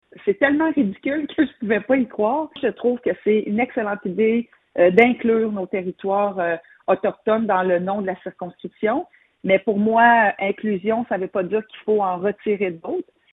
La mairesse de Nicolet, Geneviève Dubois, n’en revient pas.
Pour moi, inclusion ne veut pas dire en retirer d’autres – Geneviève Dubois, mairesse de Nicolet